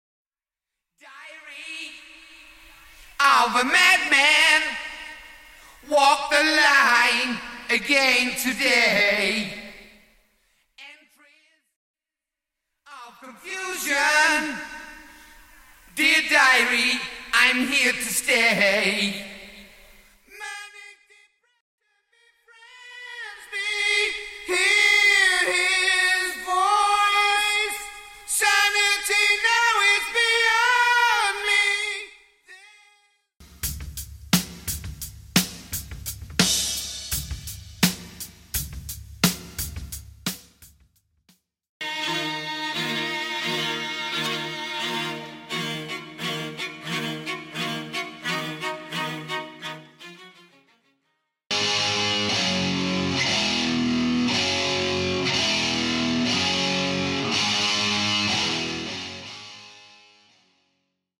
Studio Acoustic Guitars & Backing Strings Stem
Studio All Guitars Stem
Studio Bassline Guitar Stem
Studio Kick Stem
Studio Percussions Stem
Studio Violins Stem